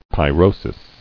[py·ro·sis]